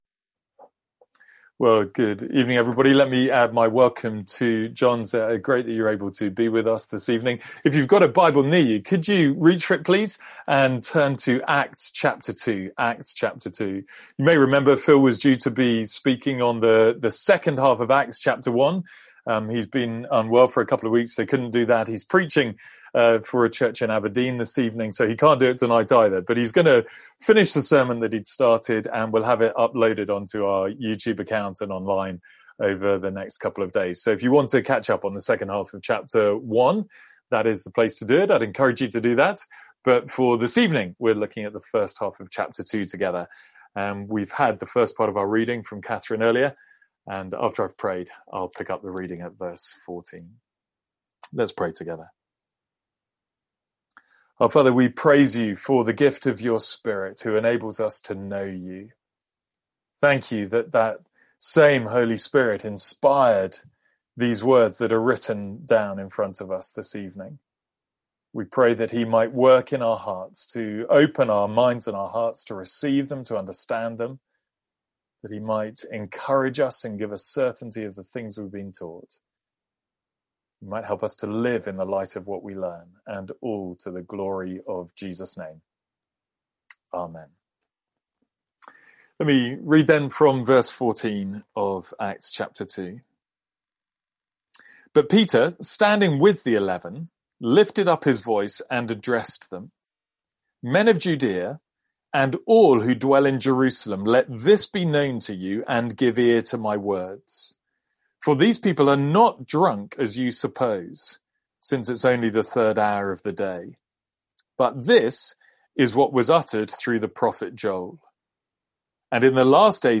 Sermons | St Andrews Free Church
From our evening series in Acts.